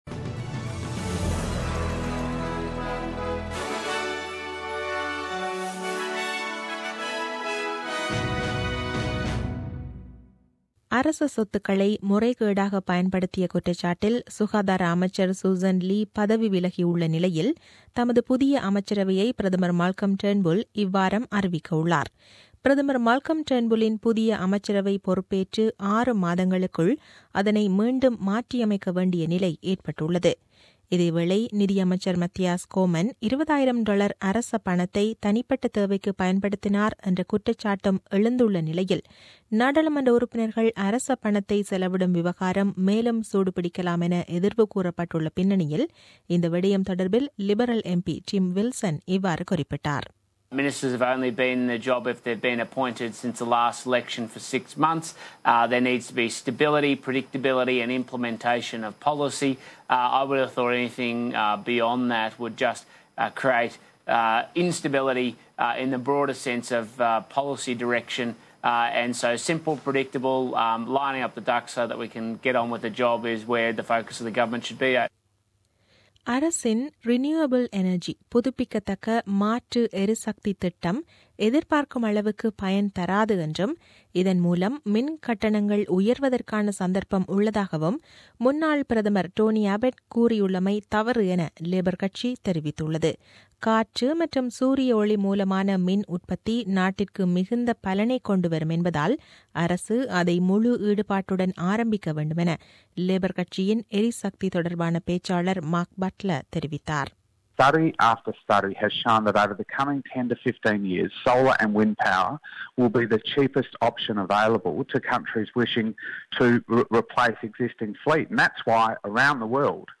The news bulletin aired on 16 January 2017 at 8pm.